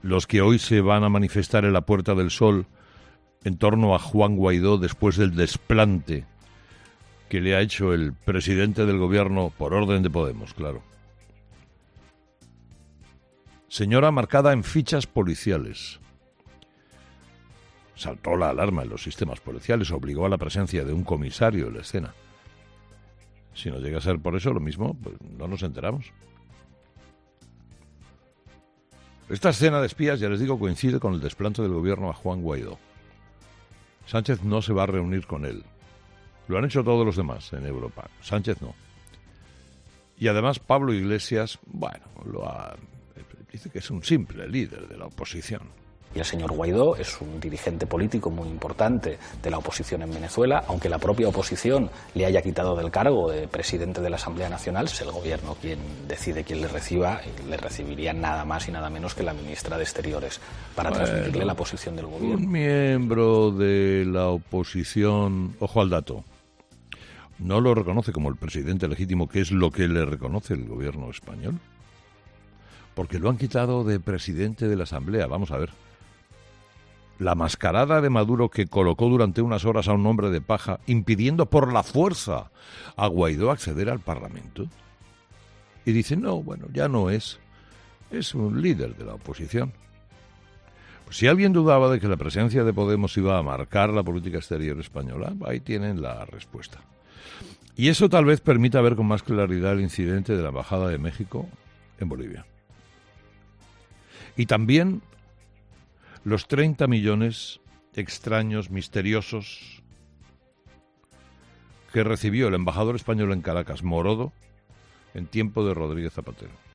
Carlos Herrera ha comenzado su monólogo de las 06.00 tratando la relación del Ejecutivo, conformado por PSOE y Podemos, con el chavismo.